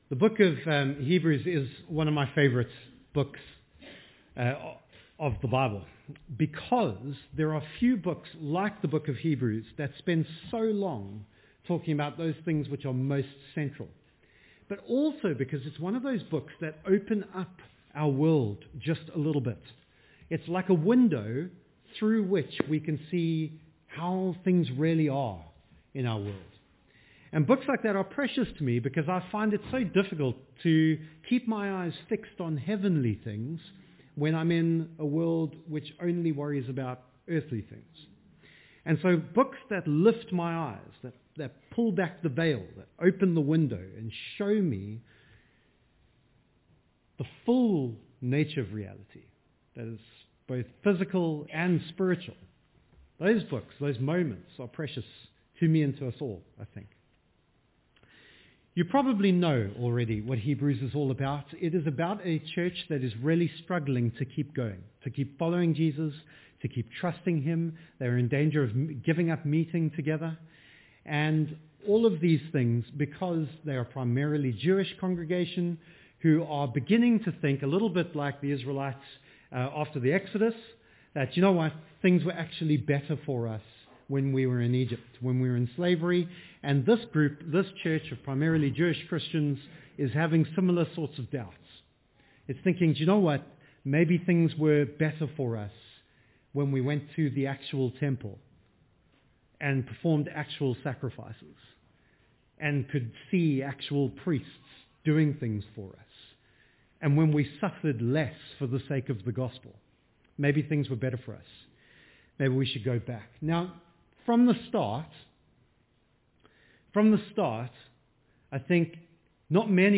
Sermons – Stowmarket Baptist Church